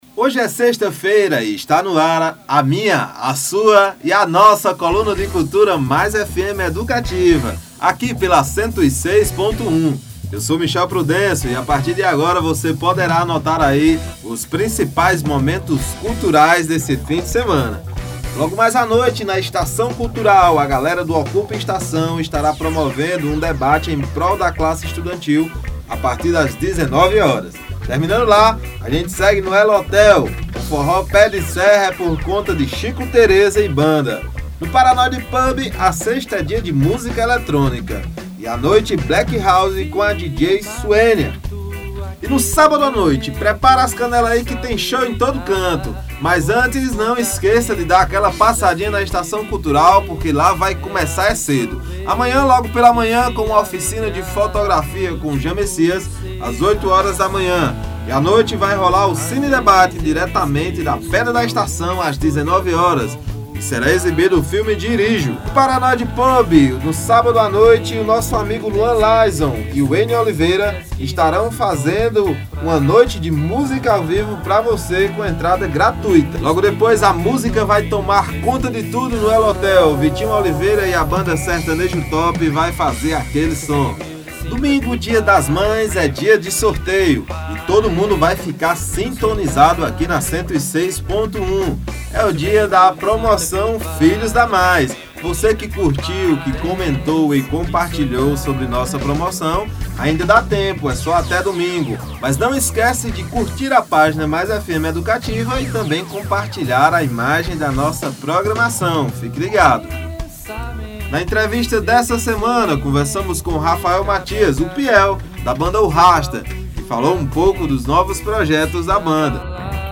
– Na trilha sonora de hoje o reggae da galera da Banda Iguatuense O Rastta nos embalou.